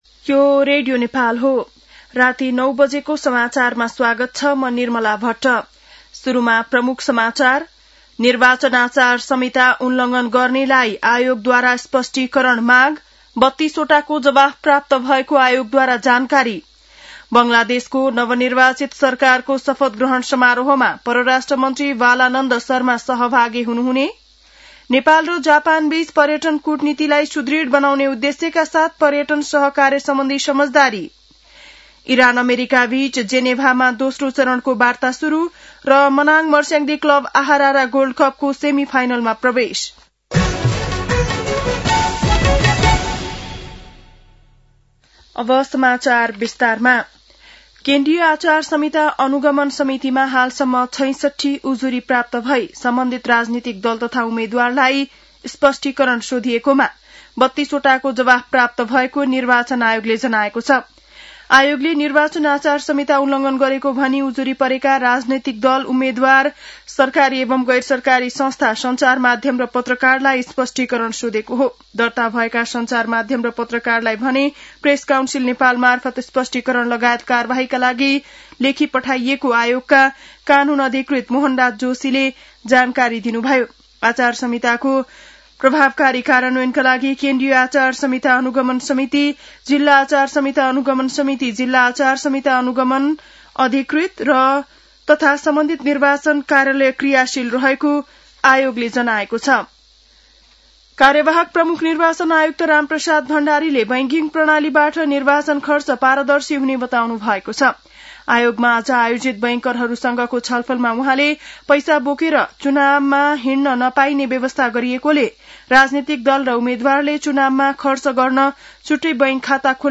बेलुकी ९ बजेको नेपाली समाचार : ४ फागुन , २०८२
9-pm-nepali-news-11-04.mp3